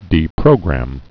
(dē-prōgrăm, -grəm)